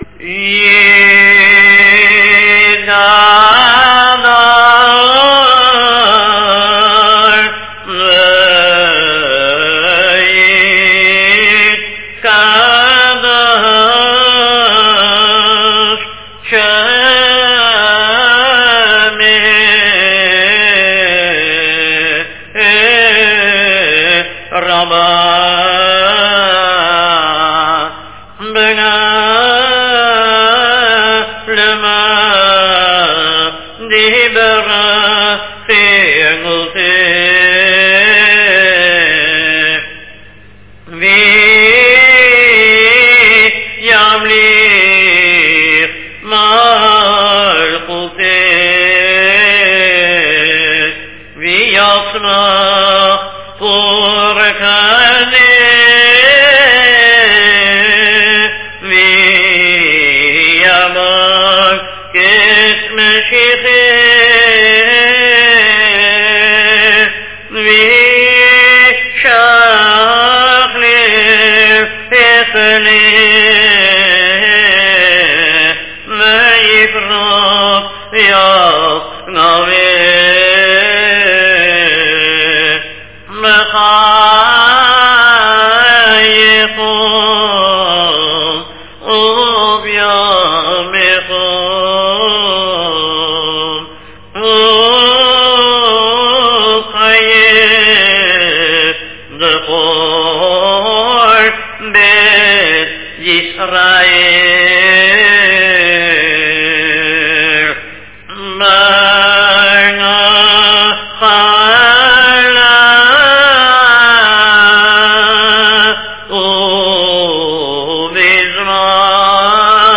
2nd day using the melody
NN24-kadish 2nd day-pag 24.mp3